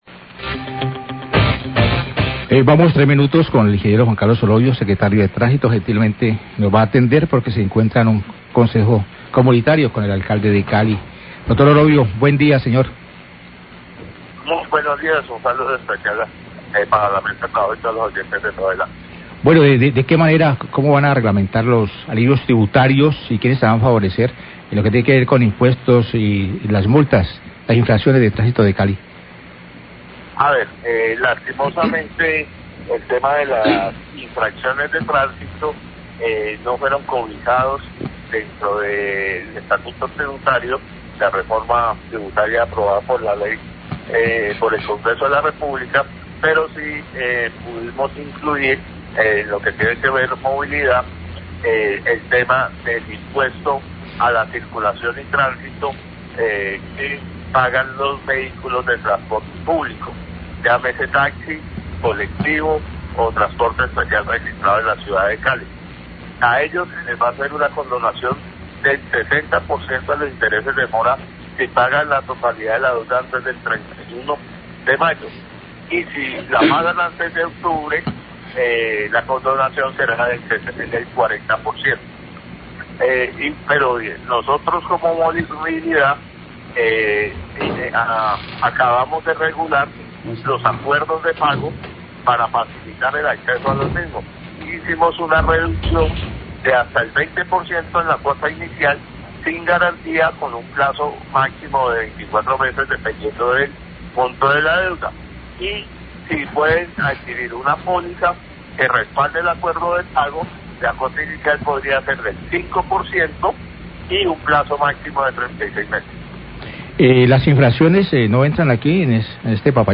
Radio
NOTICIERO RELÁMPAGO